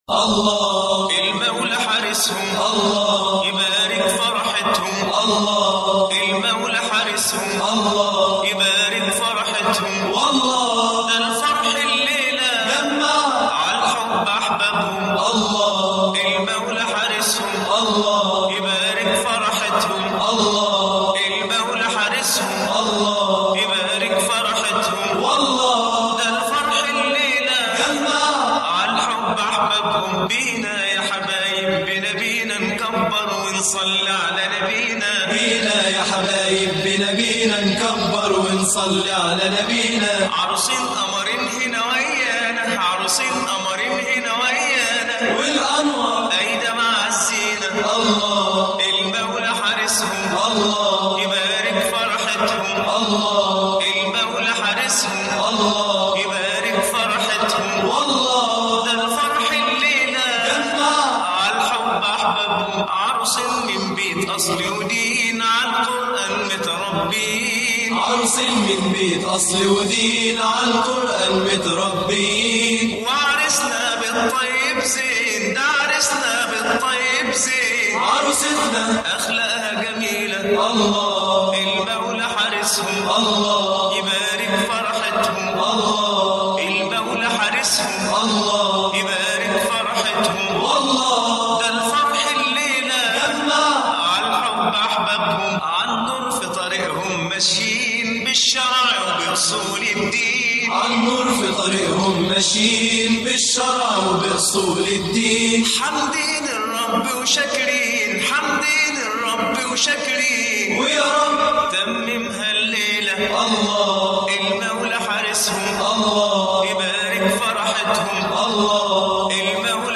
أناشيد ونغمات